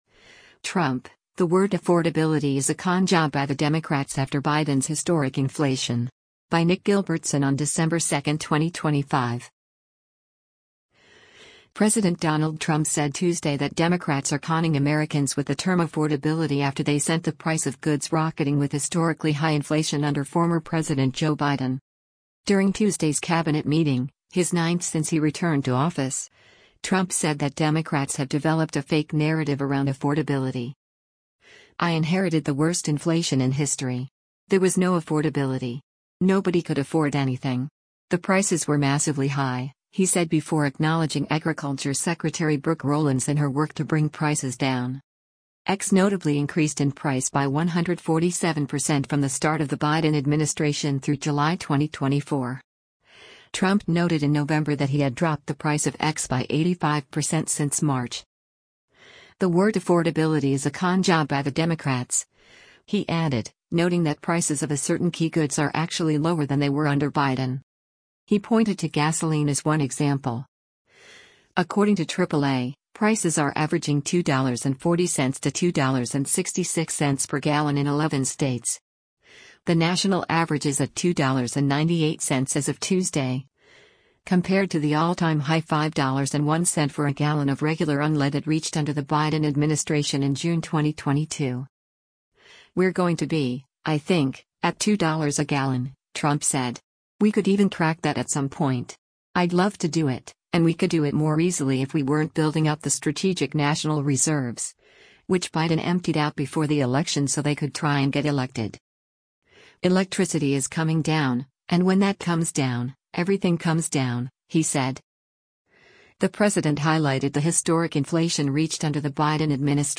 During Tuesday’s Cabinet meeting — his ninth since he returned to office — Trump said that Democrats have developed a “fake narrative” around affordability